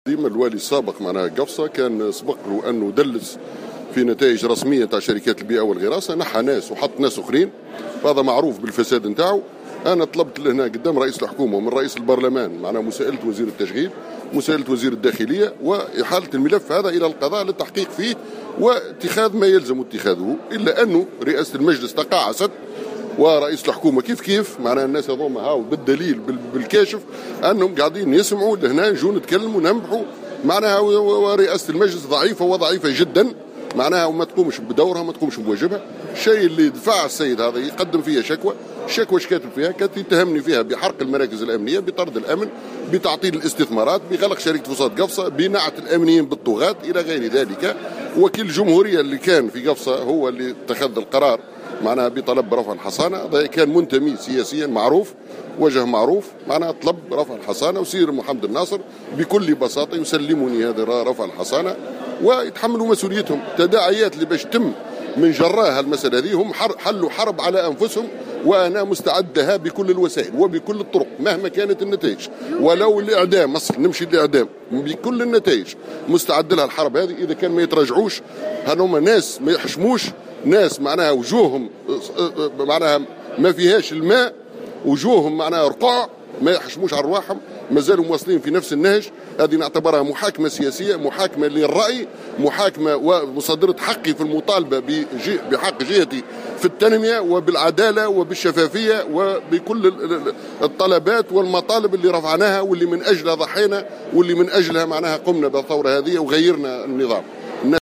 M. Hajji a indiqué, dans une déclaration à Jawhara Fm, qu'il a été surpris à la réception de cette décision de la part du président de l'Assemblée, soulignant sa grande déception.